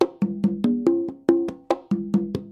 Free MP3 percussions sounds 3